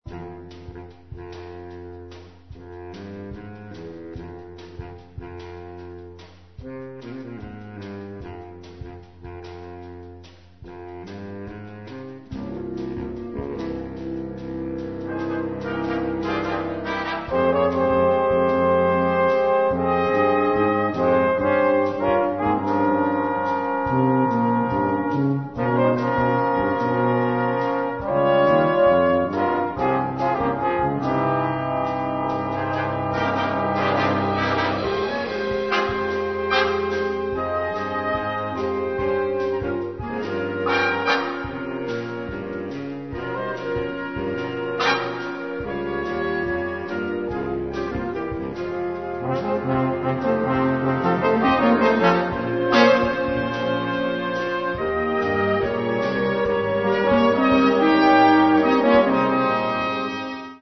semaphore - Copier - Ensemble de cuivres Epsilon